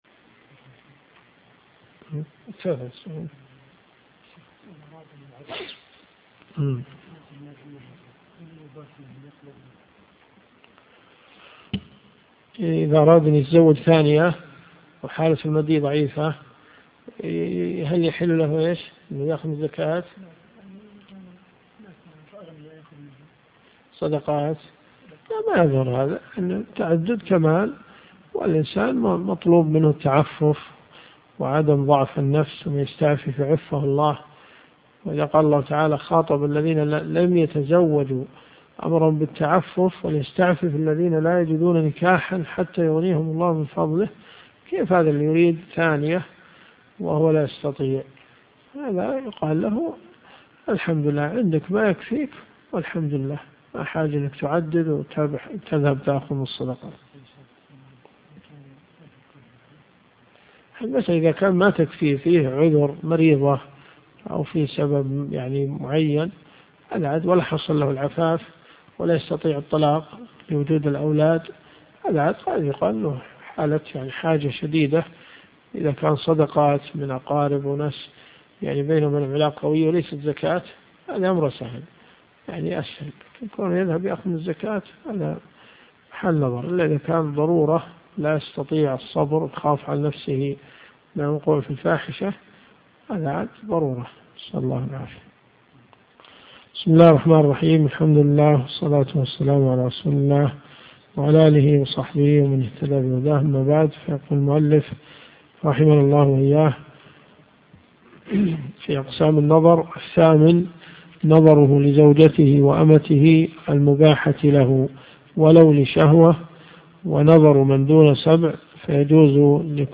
دروس صوتيه ومرئية تقام في جامع الحمدان بالرياض
الدرس في الدقيقة 1.35